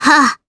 Gremory-Vox_Attack6_jp.wav